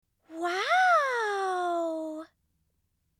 Sound Effects
Wow! Female Voice